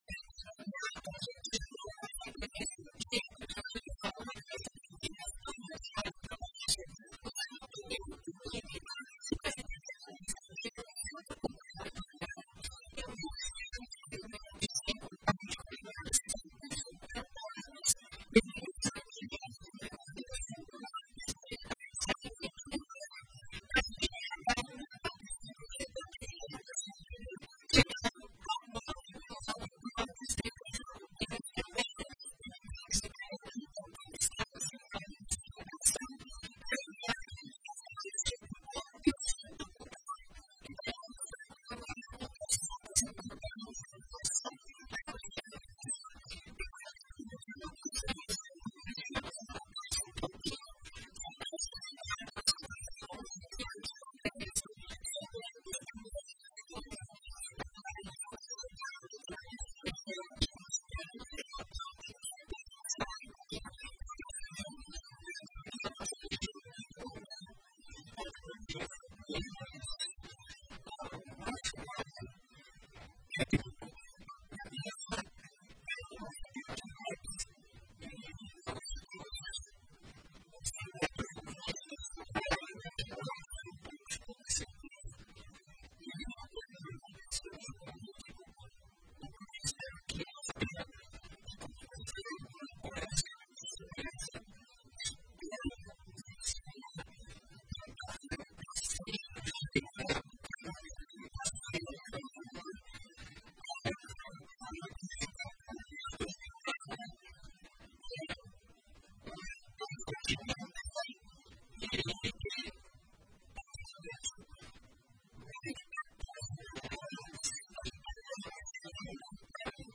entrevista-italianos-2611.mp3